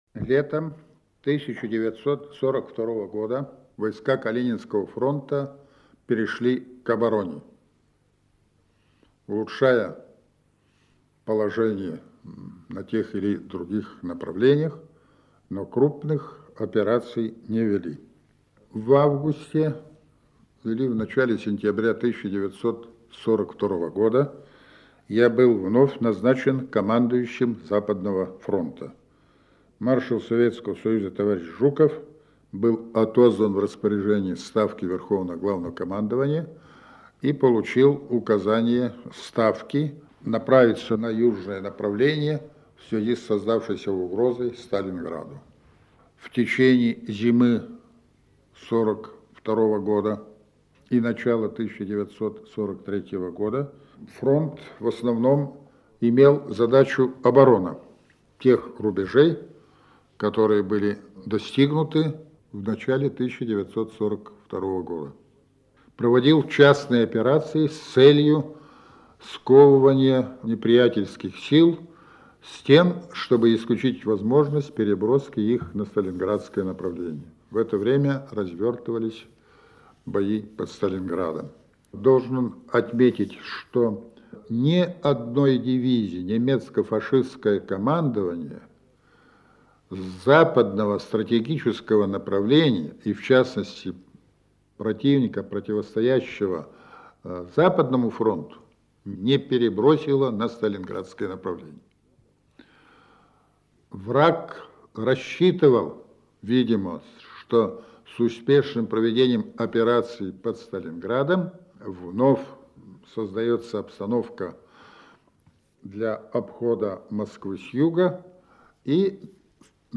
Маршал Советского Союза Иван Конев делится воспоминаниями о Курской битве (Архивная запись).